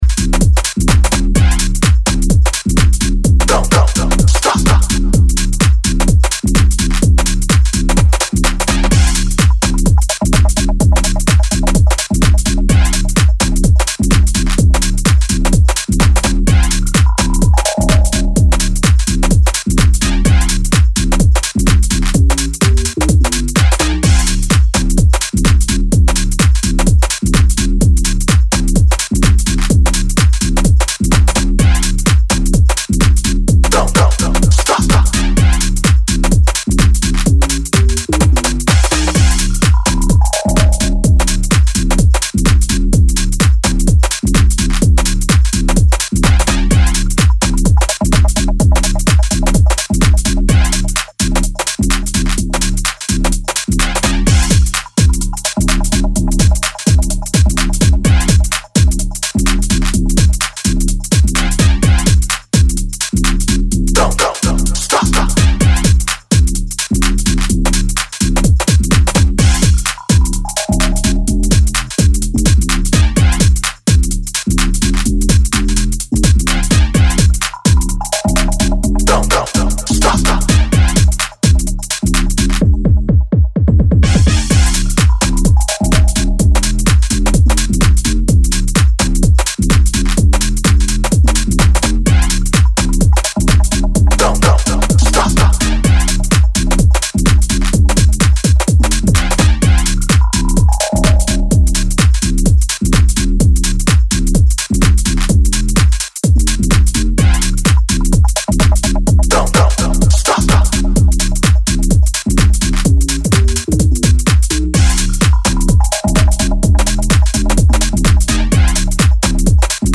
a raw, gritty garage house slammer with naughty bass.